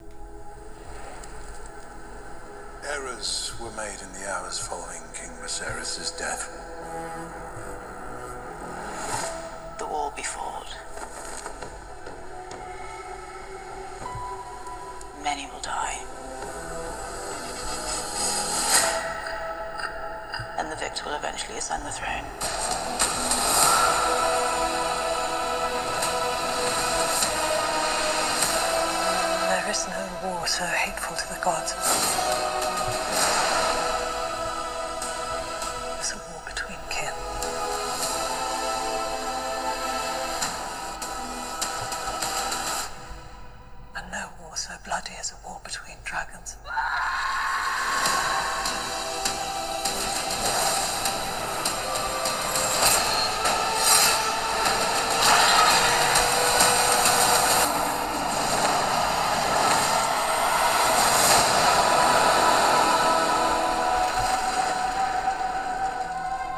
La configuración de sonido es igual que en la serie A16 nuevamente, se trata de 2 altavoces Hi-Res compatibles con Dolby Atmos. Tienen un volumen alto, excelente calidad y claridad con frecuencias equilibradas, por lo que darán una buena experiencia multimedia.